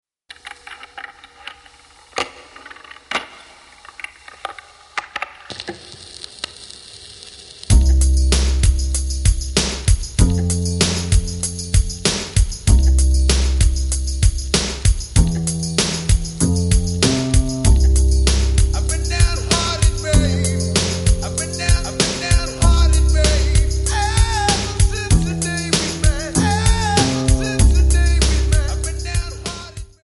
Backing track files: Rock (2136)